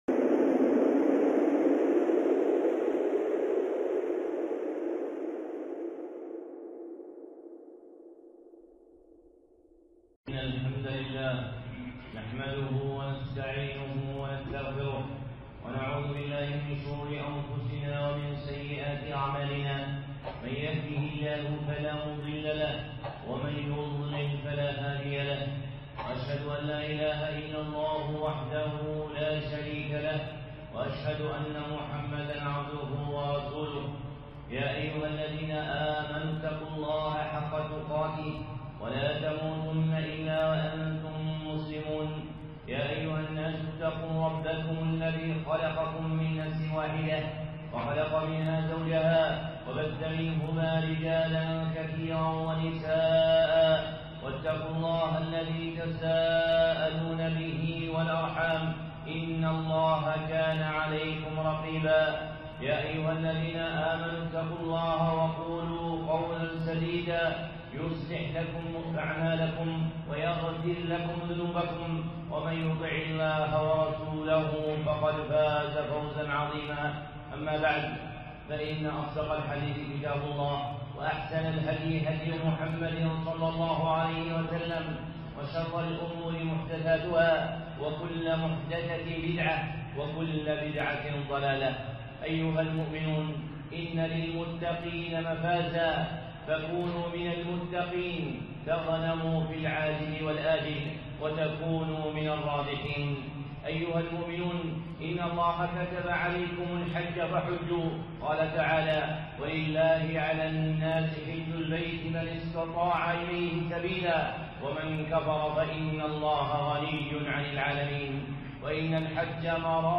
خطبة (الطريق إلى الحج المبرور)